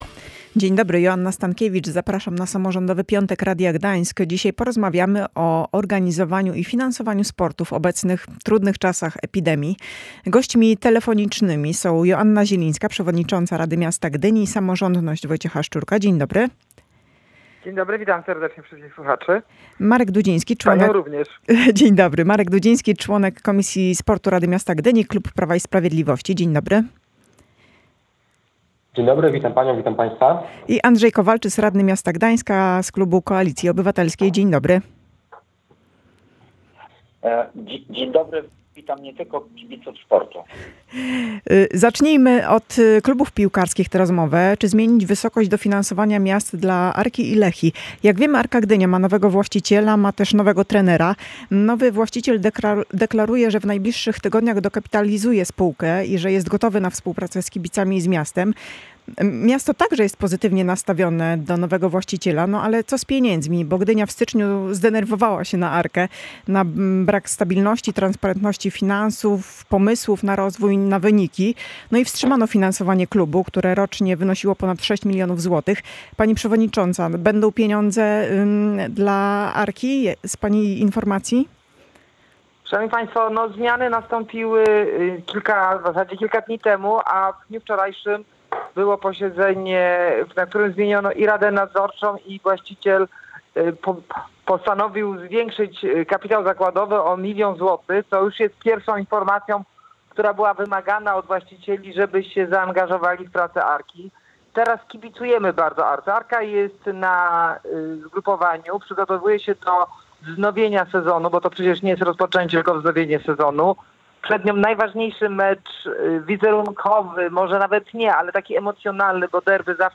W Samorządowym piątku trójmiejscy radni rozmawiali o tym, jak organizować sport w czasach epidemii. Czy zmienić wysokość dofinansowania miast dla Arki i Lechii, skoro kluby straciły wpływy?